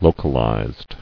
[lo·cal·ized]